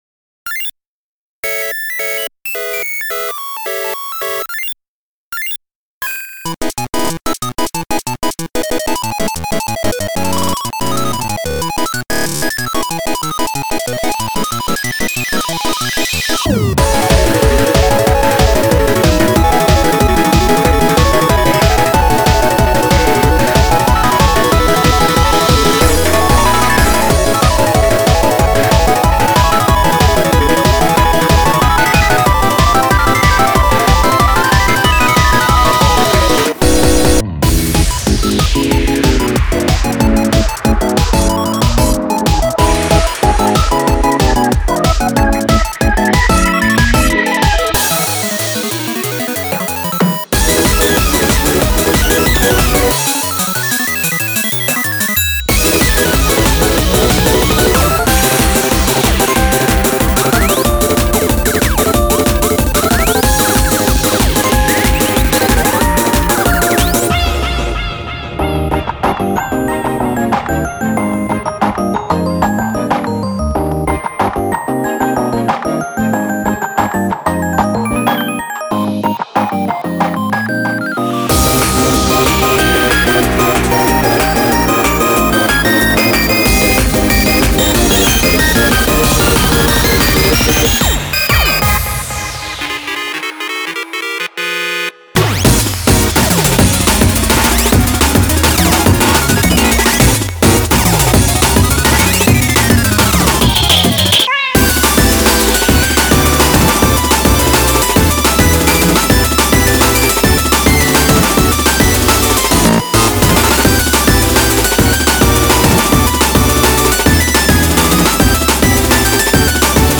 BPM93-211
Audio QualityPerfect (High Quality)
A chiptune pop song